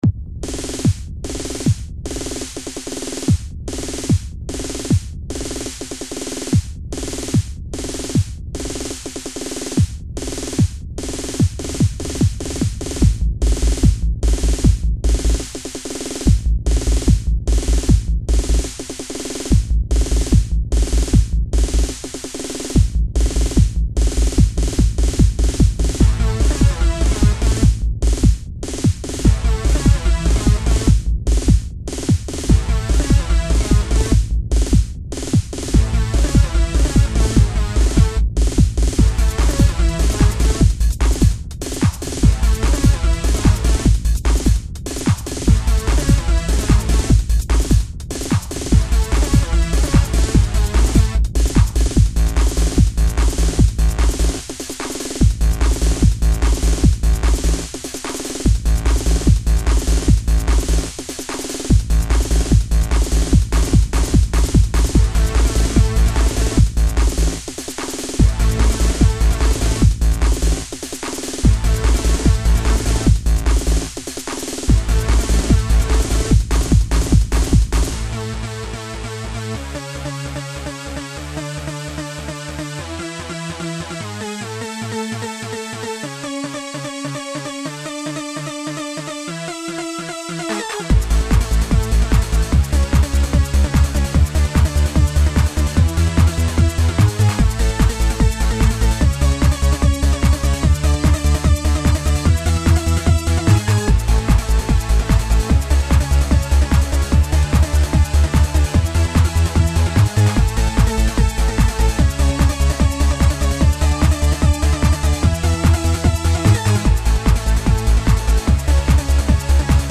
/Hard House   MIDI(34 KB)